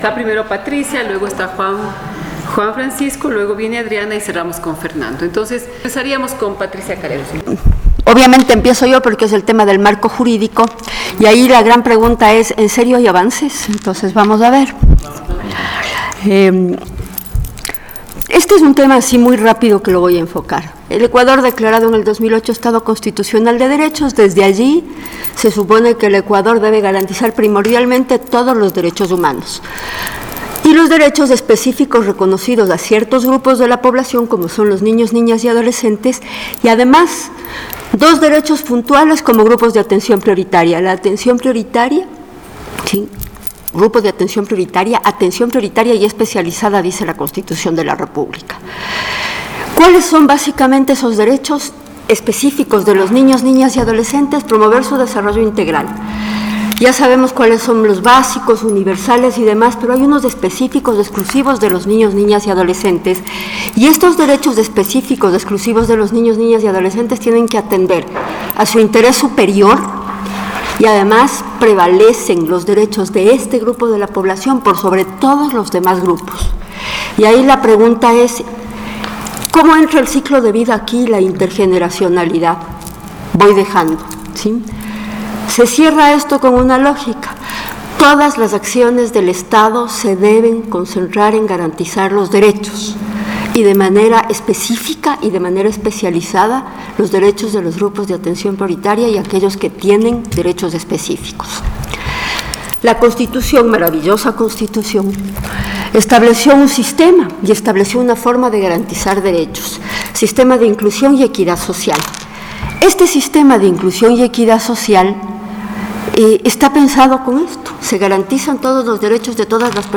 FLACSO Ecuador, su Departamento de Asuntos Públicos, y su Centro de Investigación de Políticas Públicas y Territorio - CITE, presentó el Conversatorio Niñez, adolescencia, políticas públicas y ciudad.